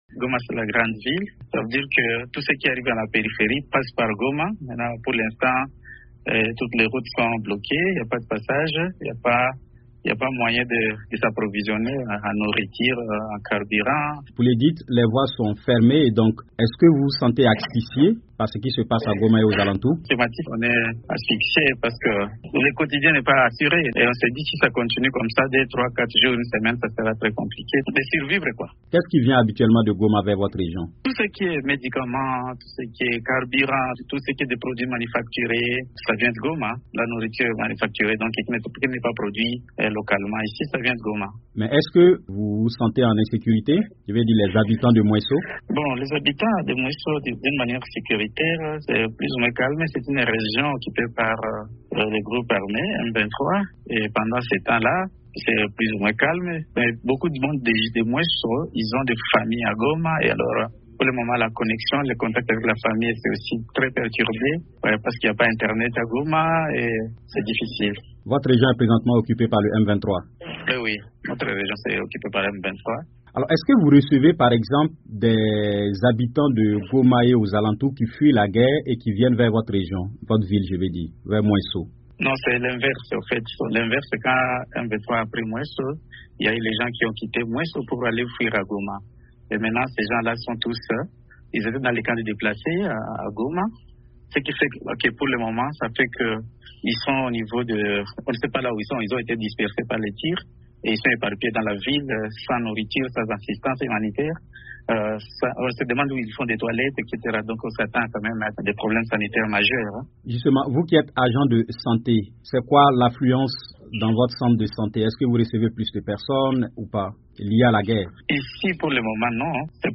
Témoignage d’un agent de santé sur la situation locale à Mwesho, à 100 km de Goma